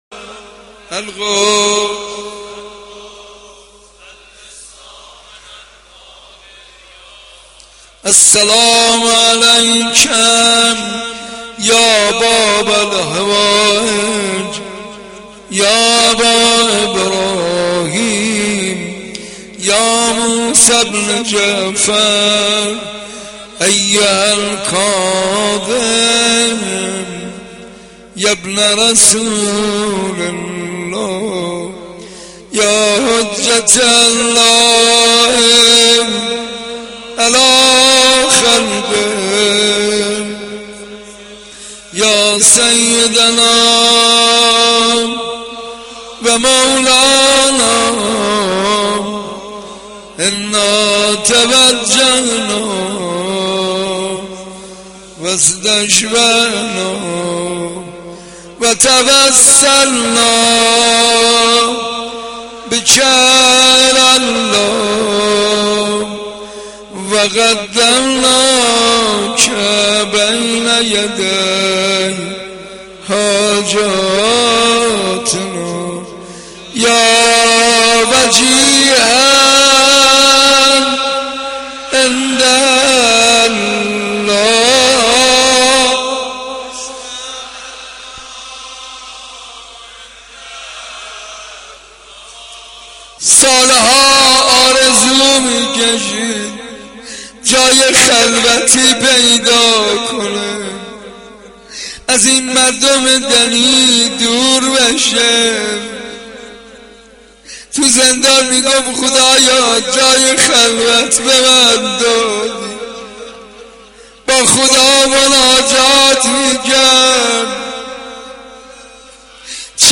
روضه امام کاظم علیه السلام
Roze-Imam-Kazem-1.mp3